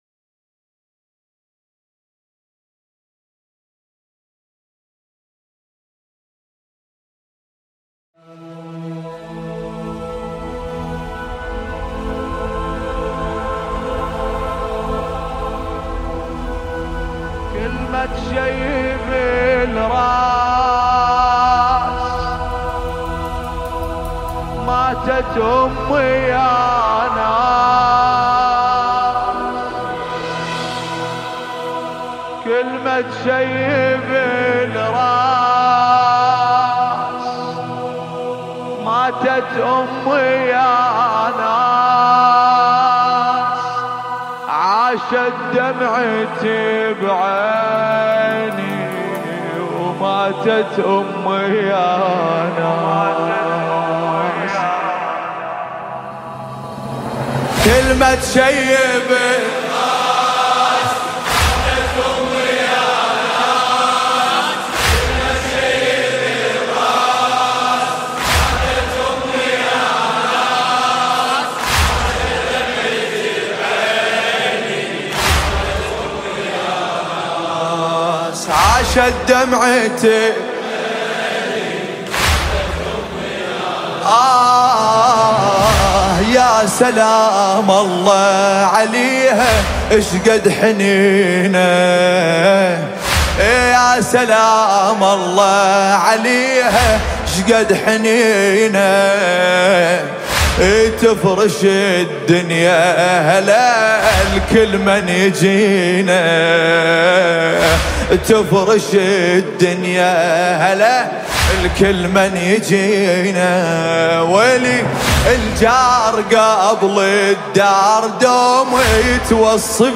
ایام فاطمیه
مداحی عربی دلنشین